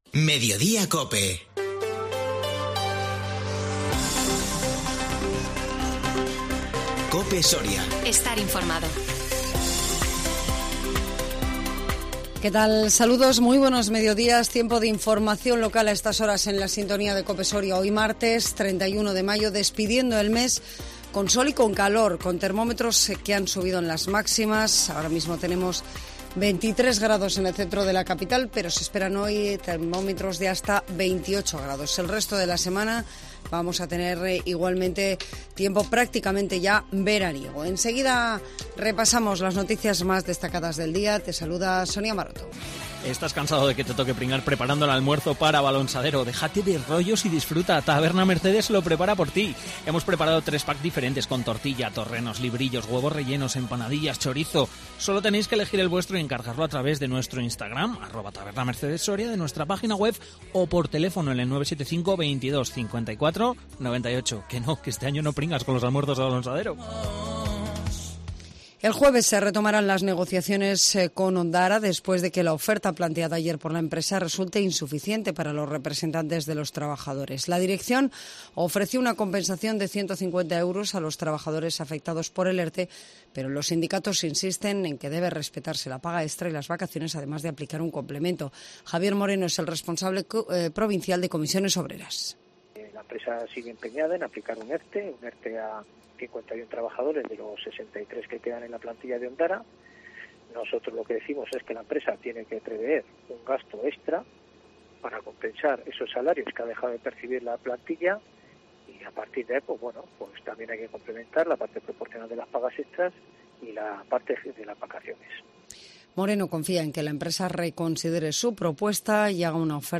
INFORMATIVO MEDIODÍA COPE SORIA 31 MAYO 2022